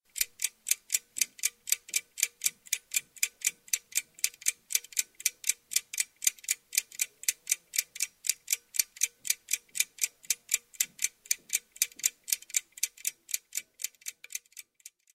Звуки тиканья
Тикающий звук часов nМерное тиканье часов nЧасовое тиканье